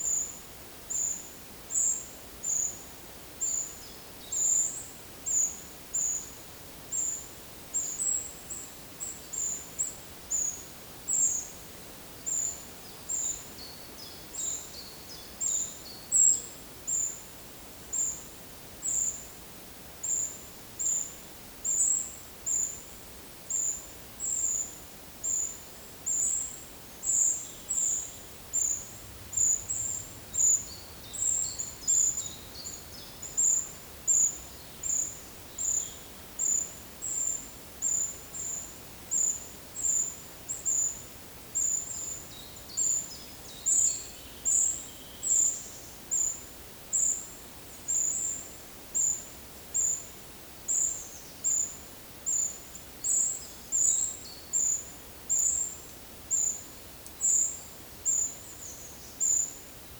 Monitor PAM
Certhia brachydactyla
Certhia familiaris